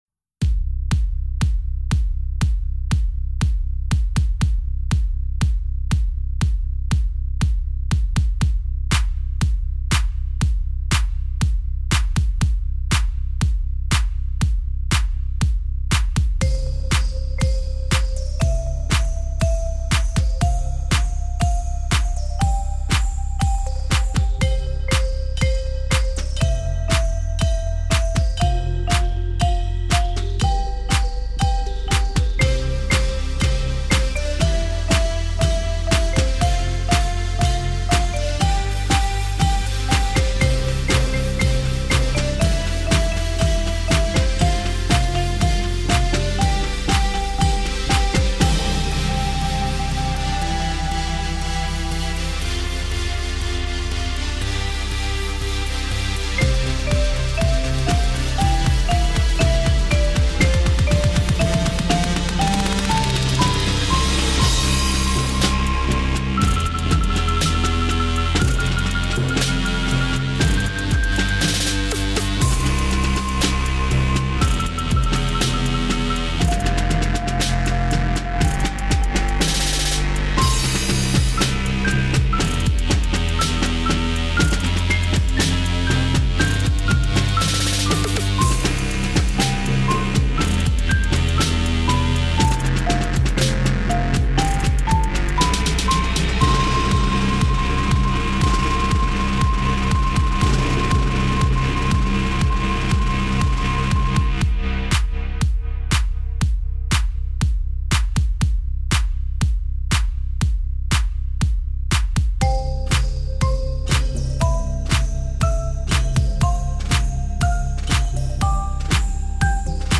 Gattung: Percussion Solo oder Percussion Ensemble
Besetzung: Instrumentalnoten für Schlagzeug/Percussion